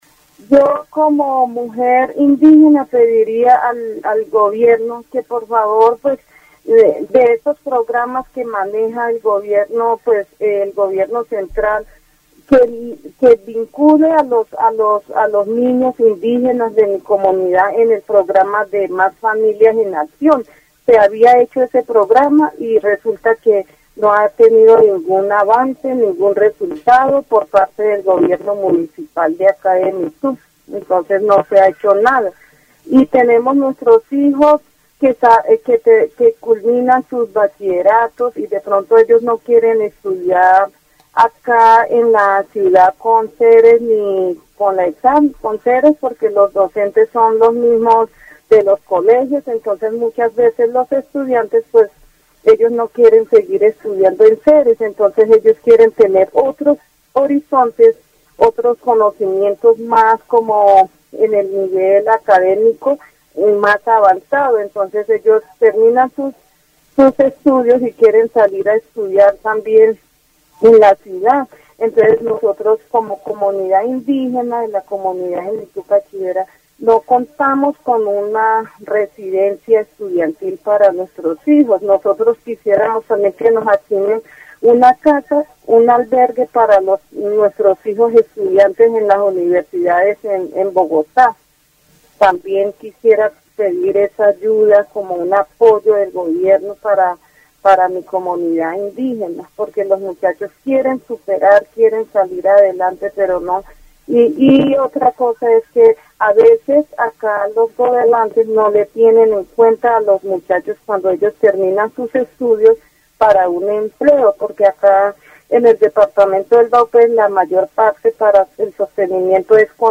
La entrevistada solicita al gobierno apoyo para los niños indígenas a través de programas educativos y residencias estudiantiles.